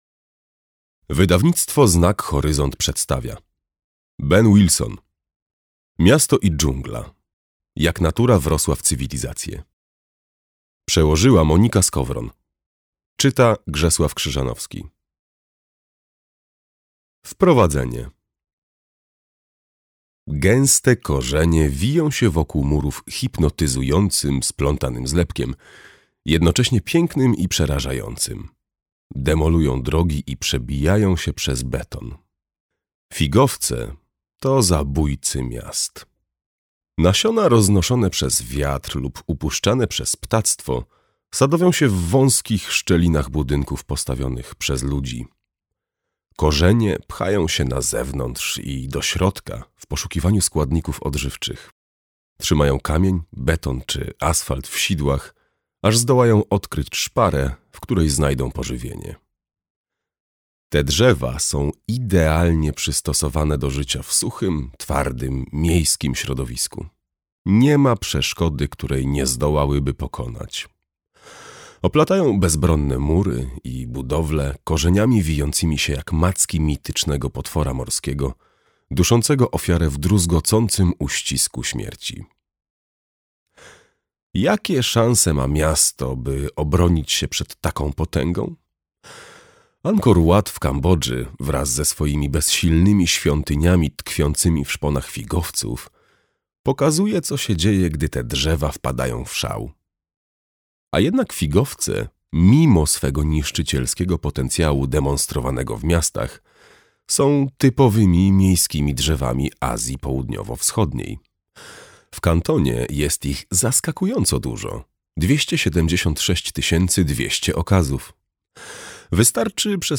Miasto i dżungla - Wilson Ben - audiobook